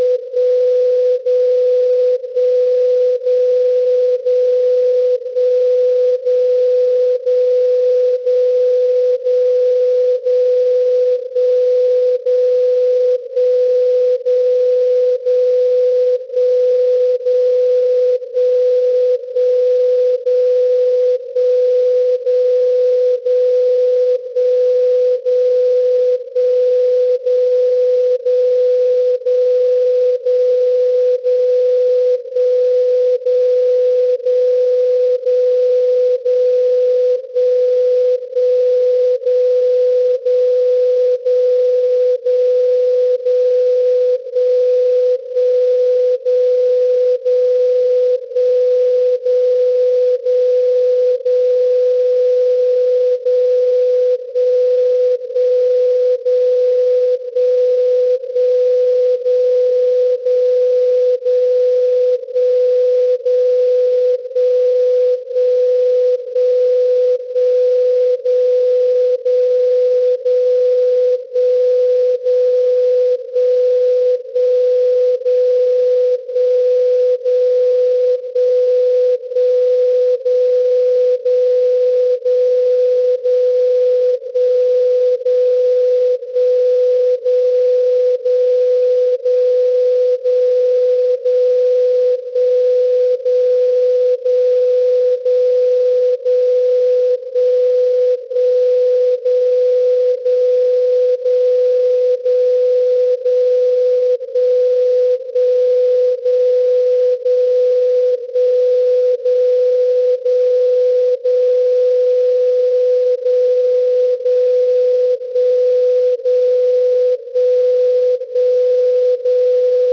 Logisch 0: Absenkung für 100 ms.
Logisch 1: Absenkung für 200 ms.
Sekunde 59: In der letzten Sekunde jeder Minute erfolgt keine Absenkung.
dcf77.wav